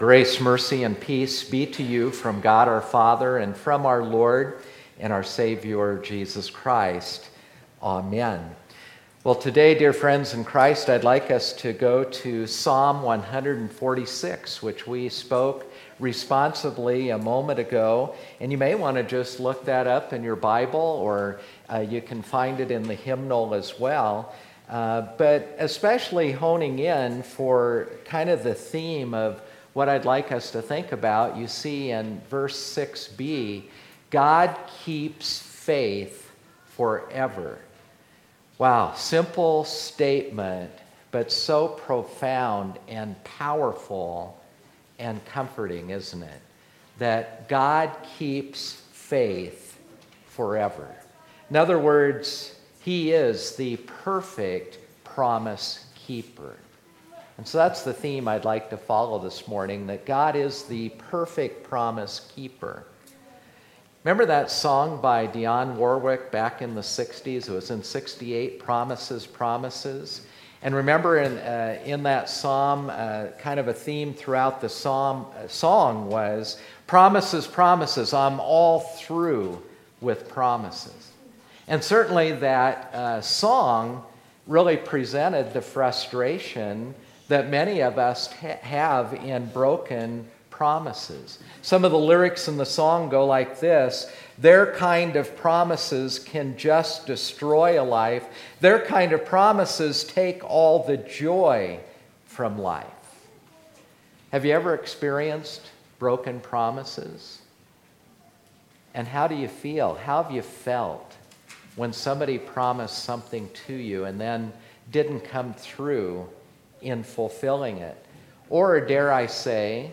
Sermon2018-09-09